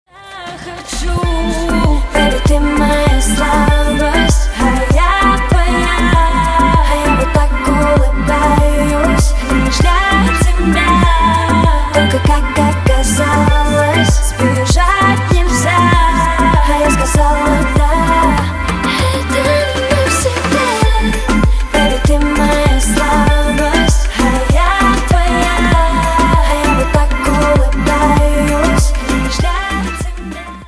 • Качество: 320, Stereo
поп
RnB